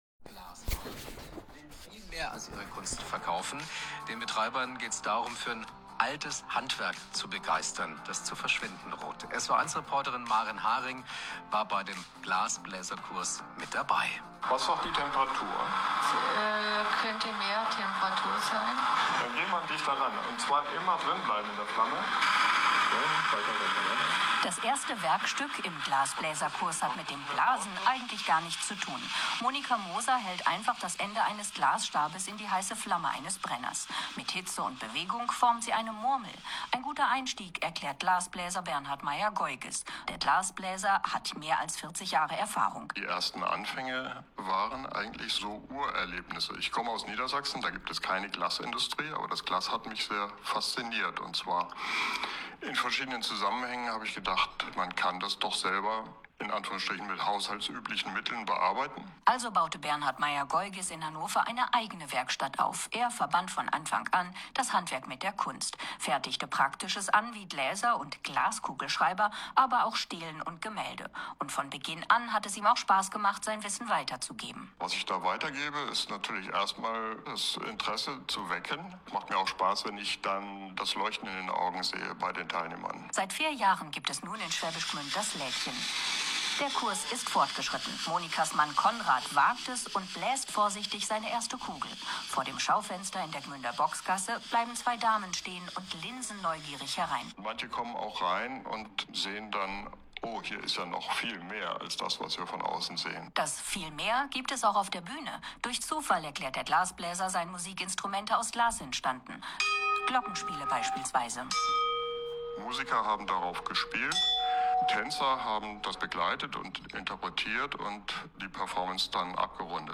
SWR Bericht über die GLASHART - Workshops Glasblasen
Die Sendung erfolgt bei SWR1 am Freitag, 14.11.2025, 17:40 sowie am Mittwoch, 19.11.2025, ab 7:00 Uhr.